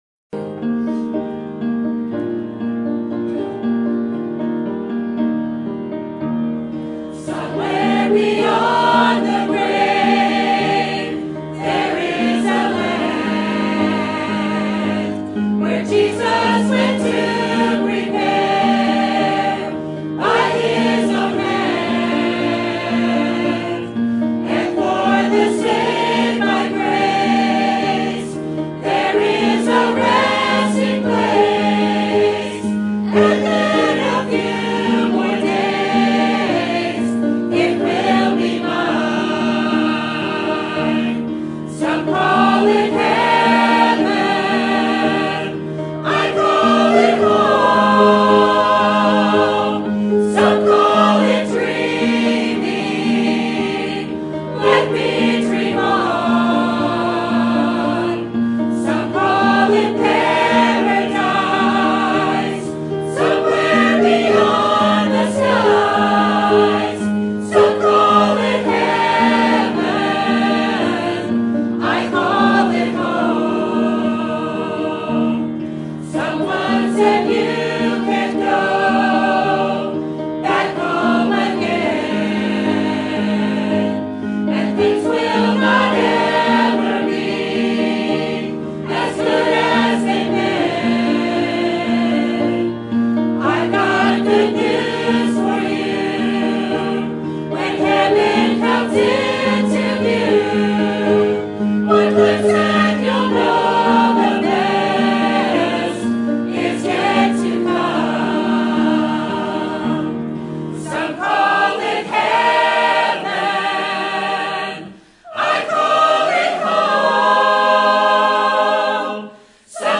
Sermon Topic: General Sermon Type: Service Sermon Audio: Sermon download: Download (28.06 MB) Sermon Tags: 2 Corinthians Heaven Tent Building